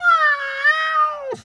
cat_2_meow_emote_02.wav